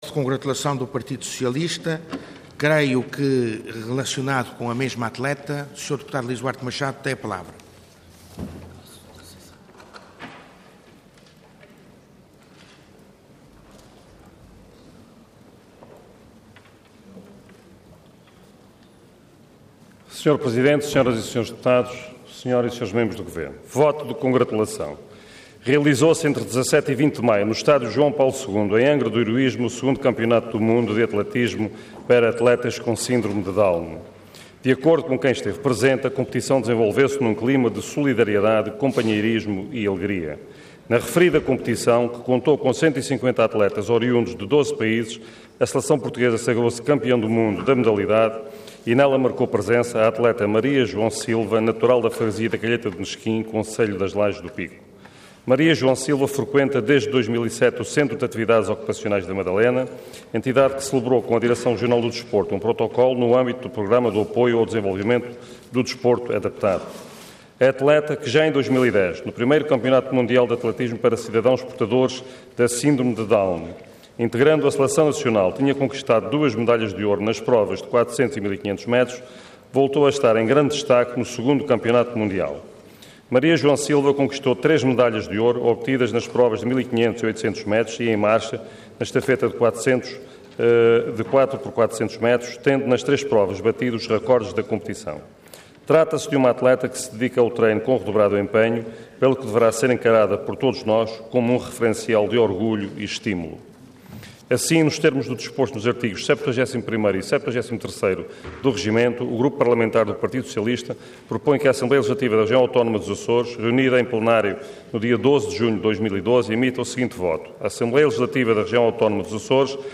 Assembleia Legislativa da Região Autónoma dos Açores
Intervenção
Deputado